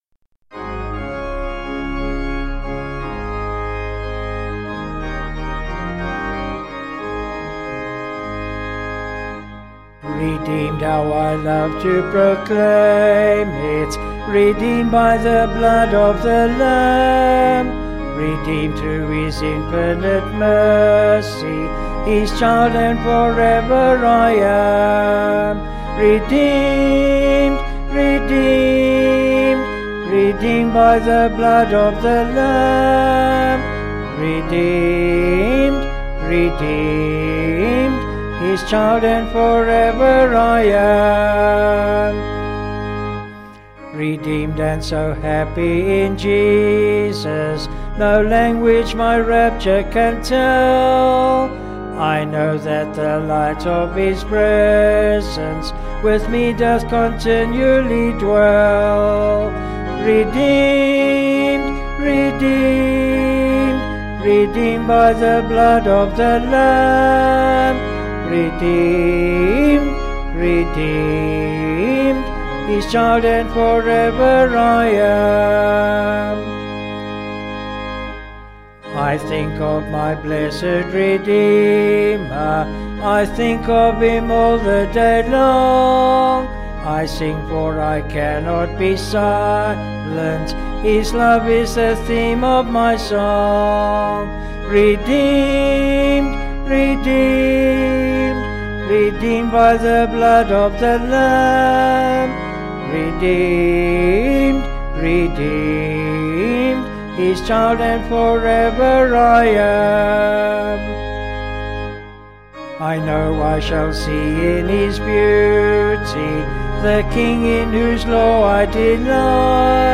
Vocals and Organ   263.6kb Sung Lyrics